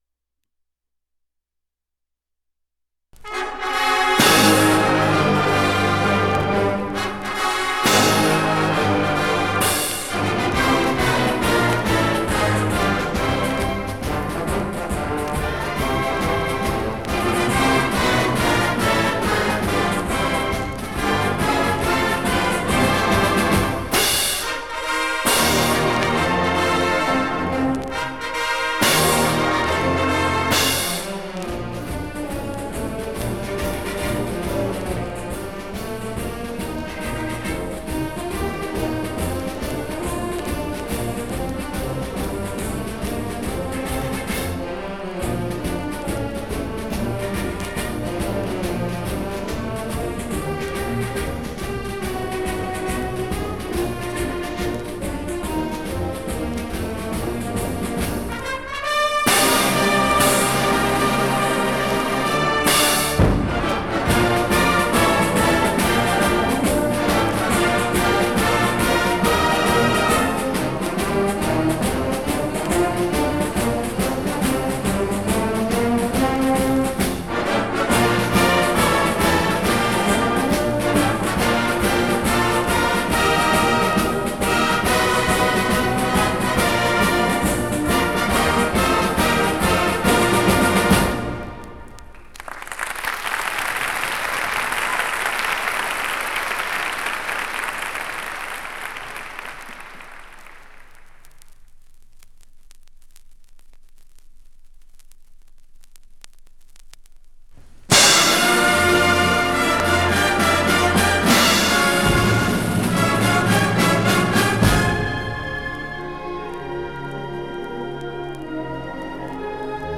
1972 Music in May band and chorus performance recording · Digital Exhibits · heritage
d86ceefd3ef27ea5c6395fda034afcd49ff17fa7.mp3 Title 1972 Music in May band and chorus performance recording Description An audio recording of the 1972 Music in May band and chorus performance at Pacific University. Music in May is an annual festival that has been held at Pacific University since 1948. It brings outstanding high school music students together on the university campus for several days of lessons and events, culminating in the final concert that this recording preserves.